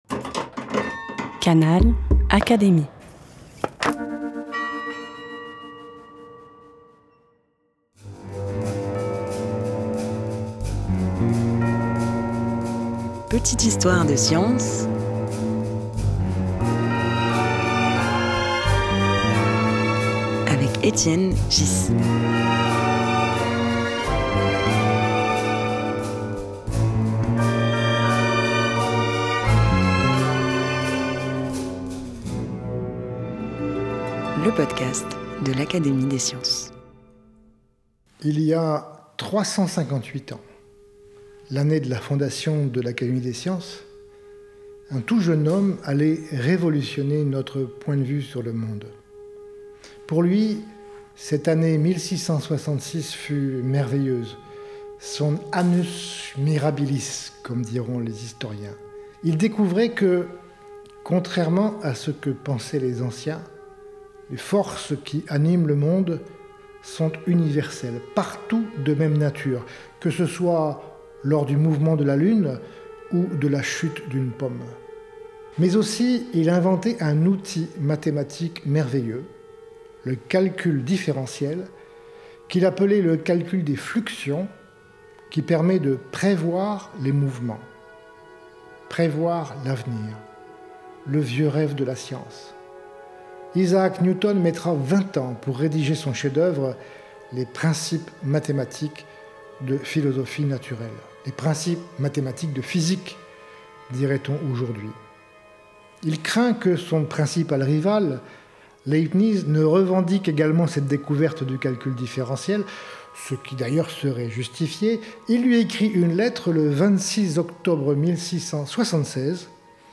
Dans cet épisode, Étienne Ghys reprend un discours prononcé sous la Coupole intitulé « Harmonie et Chaos ».